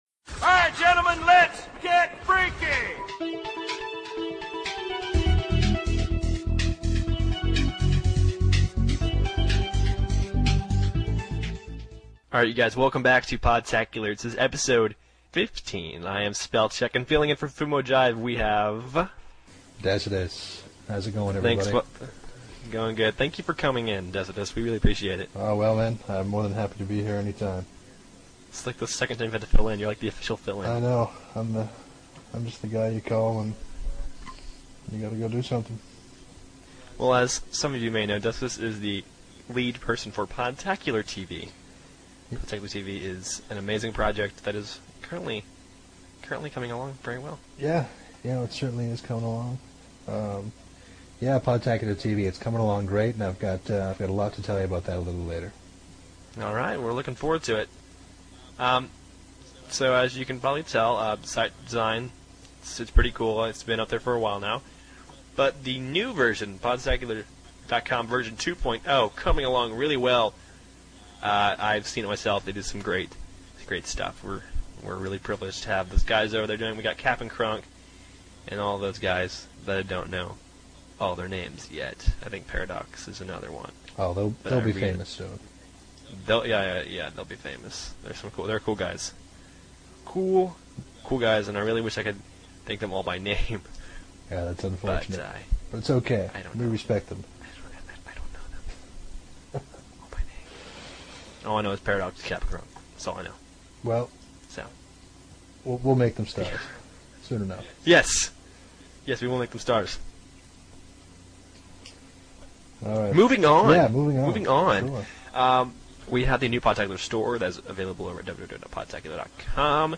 Hope you like our new higher quality shows!